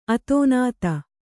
♪ atōnāta